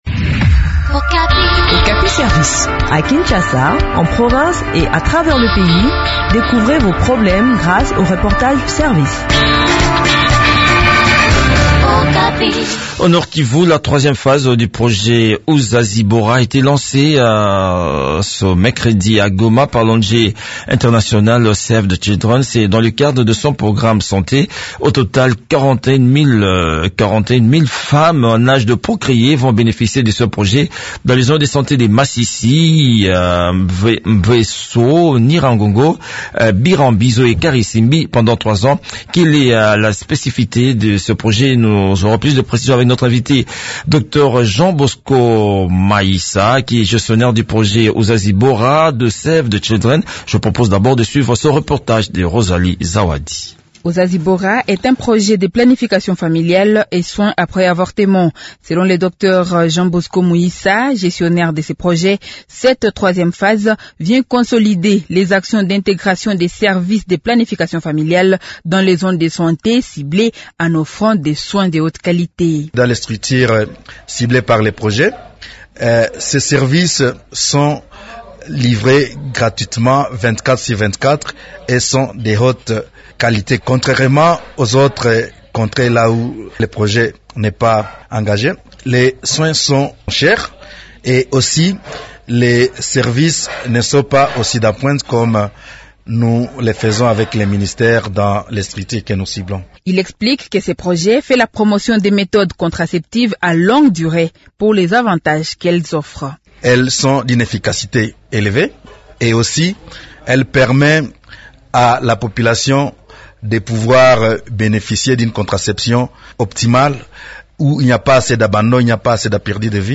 Okapi service, Émissions / cachot, maison carcérale, Détention